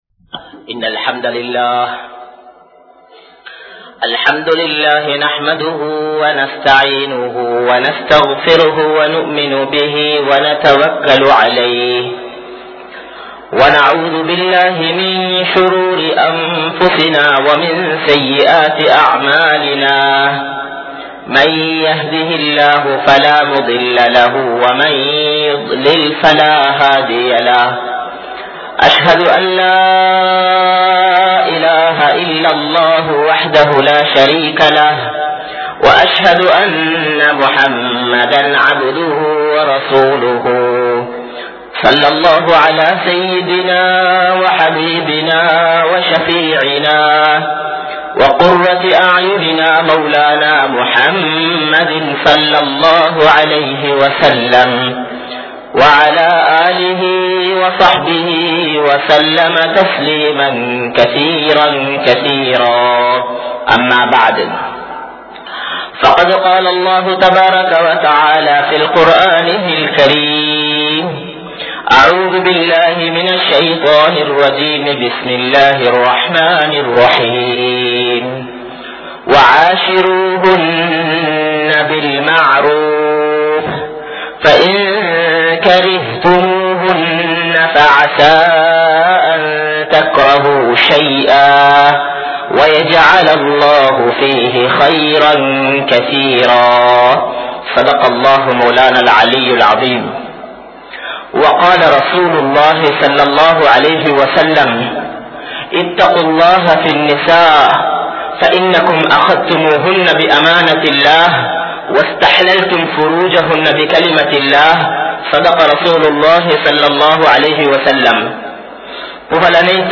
Santhosamaana Kudumba Vaalkai (சந்தோசமான குடும்ப வாழ்க்கை) | Audio Bayans | All Ceylon Muslim Youth Community | Addalaichenai
Kollupitty Jumua Masjith